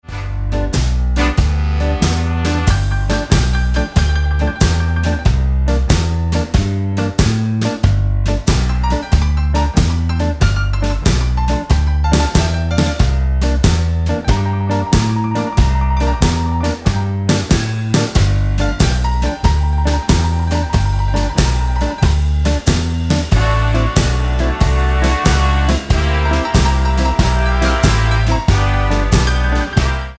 Tonart:G Ohne Lead Guitar ohne Chor